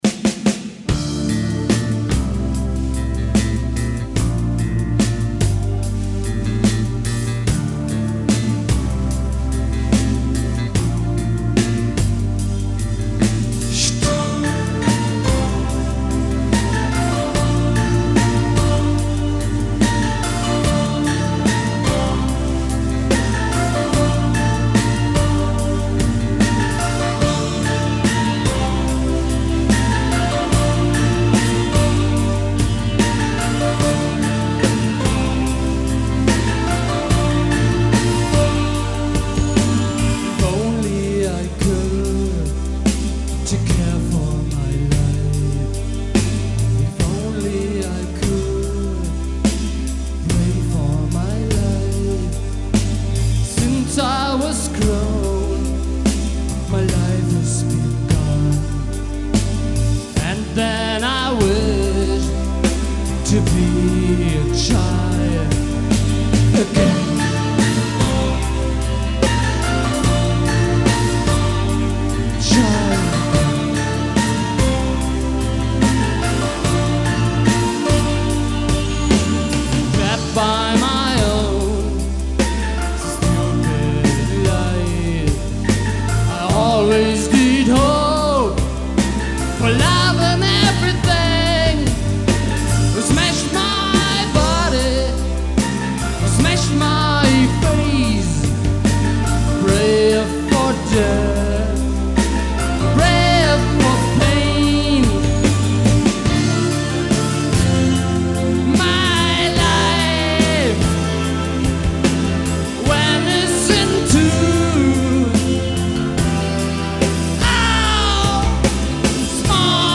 Um das Thema mal wieder auf "Early Tapes" zu lenken, hier eine Proberaumaufnahme von 1989. Ich spielte damals in einer Band mit dem obskuren Namen "Dr. No & The Injury" Aufgenommen hab ich das Ganze damals glaub ich mit nem 4-Spur Kassettenrecorder, dem Yamaha MT1X.